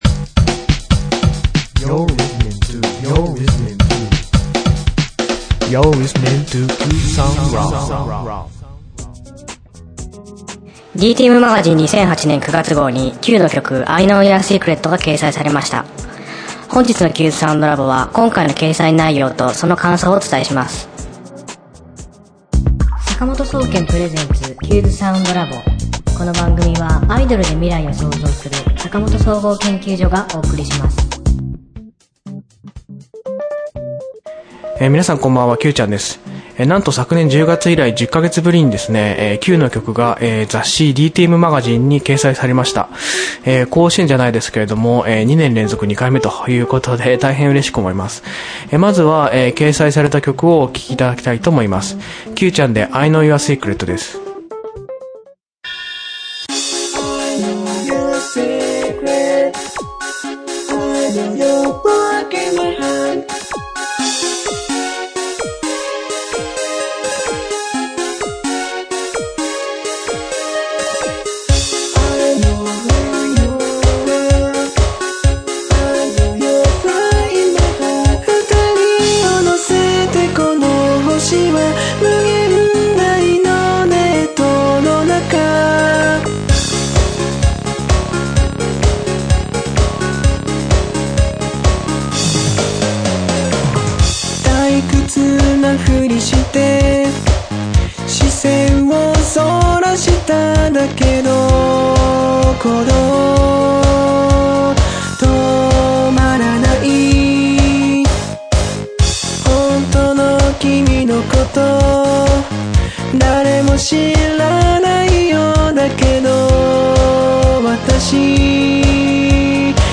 今週の挿入歌